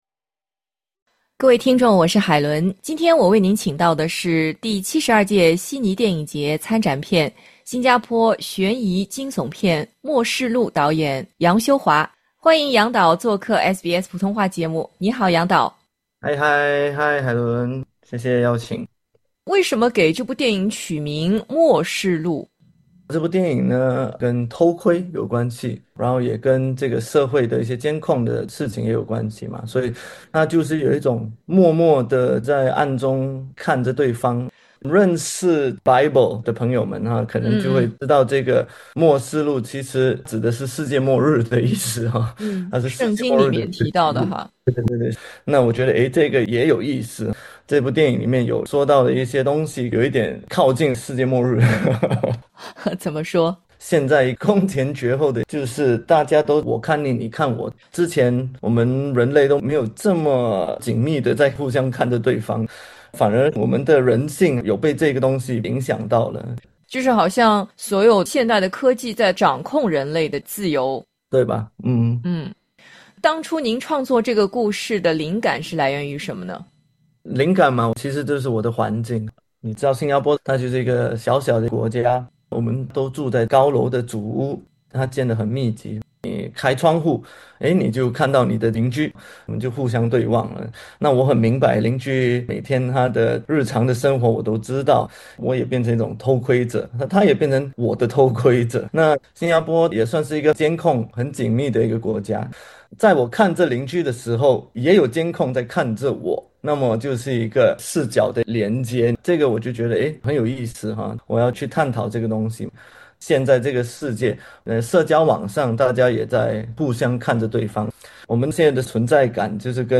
第 72 届悉尼电影节将于本月 15 日闭幕，正在热映的新加坡悬疑惊悚片《默视录》（Stranger Eyes）凭借独特视角成为焦点！点击图标，收听采访。